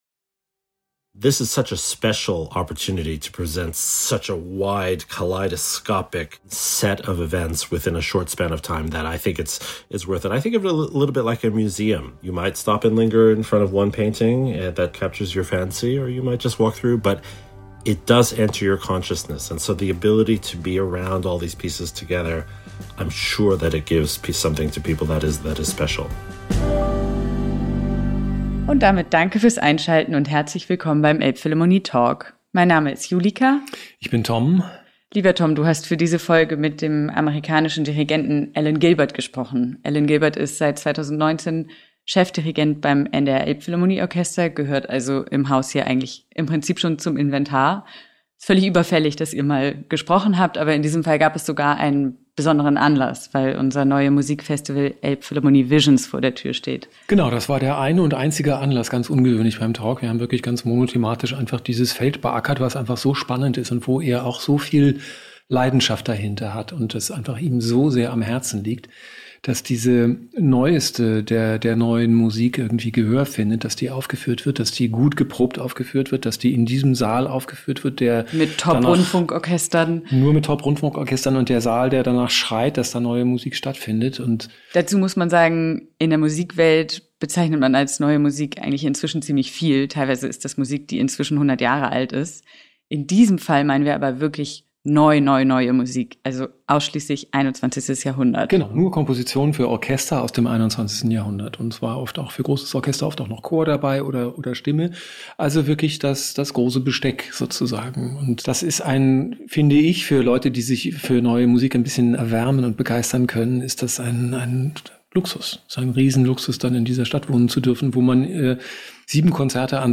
So klingt die Gegenwart: »Elbphilharmonie VISIONS« 2025 – Elbphilharmonie Talk mit Alan Gilbert
Im »Elbphilharmonie Talk« spricht Gilbert über seine Freude an der Verantwortung für die Musik unserer Zeit.